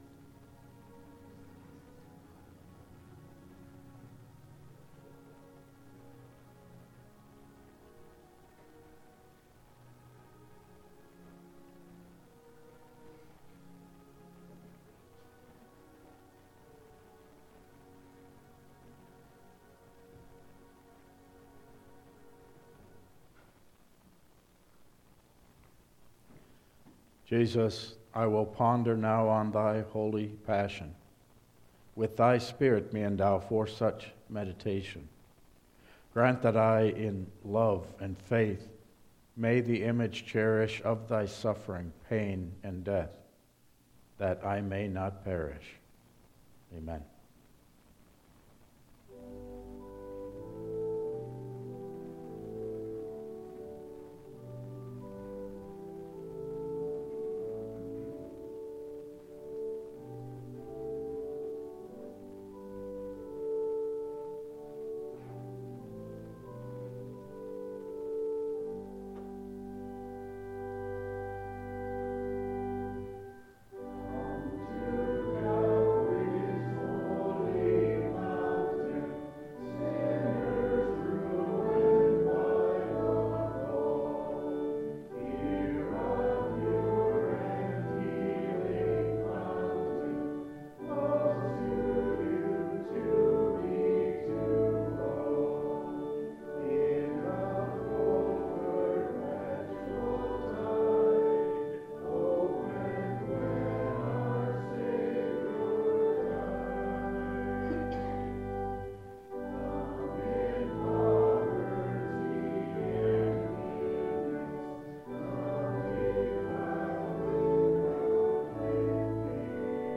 Download Files Printed Sermon and Bulletin
Service Type: Lenten Service